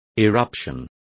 Complete with pronunciation of the translation of eruption.